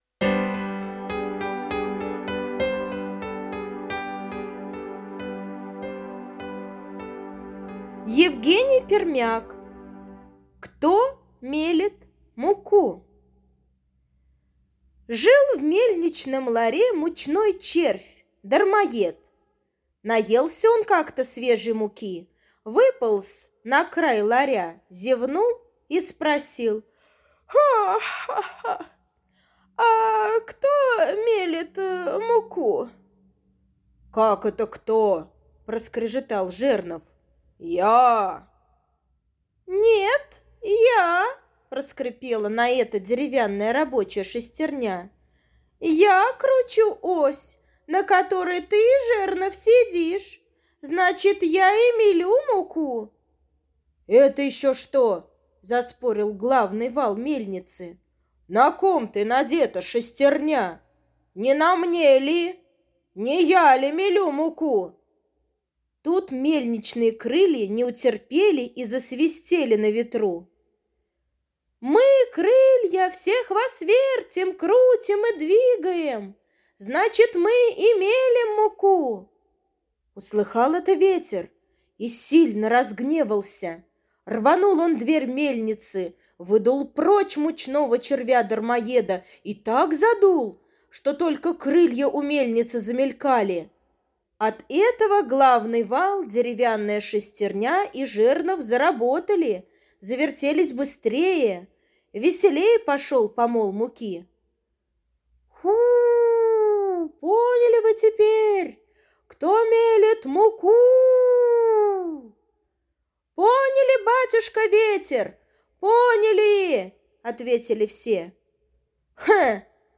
Аудиосказка «Кто мелет муку»